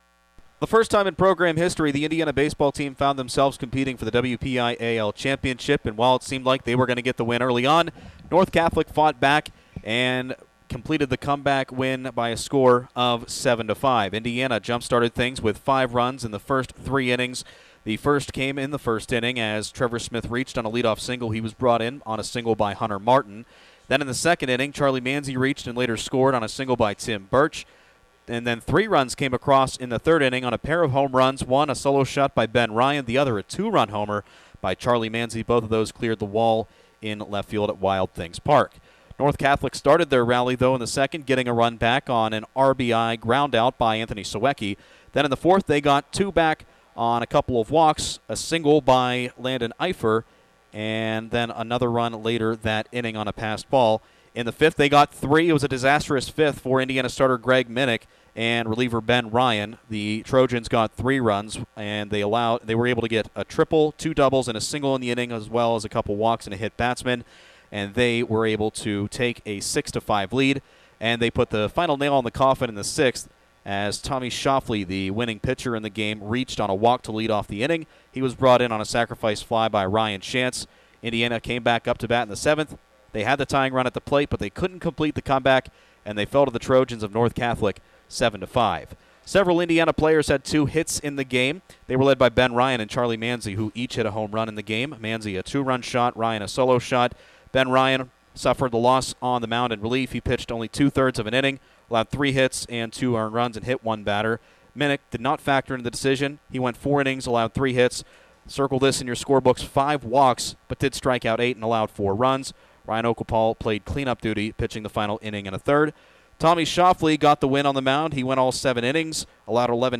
hs-baseball-indiana-recap.mp3